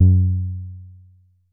BASS1 F#2.wav